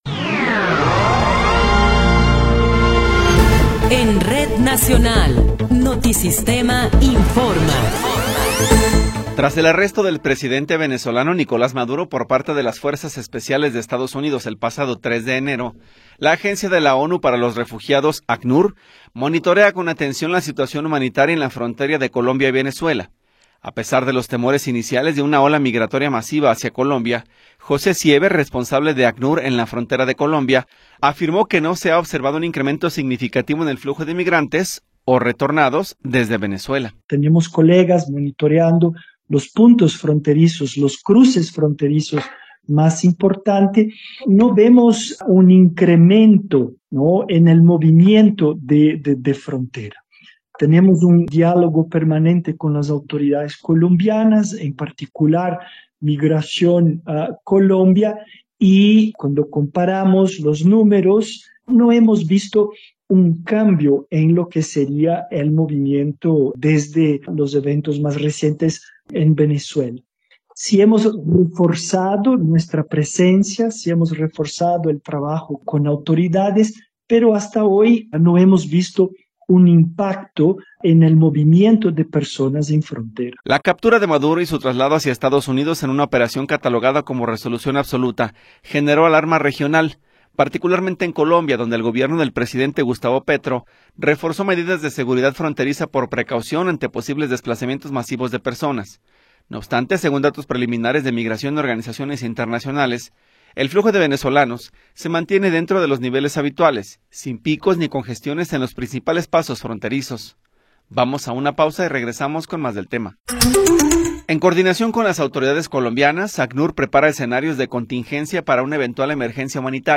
Noticiero 13 hrs. – 18 de Enero de 2026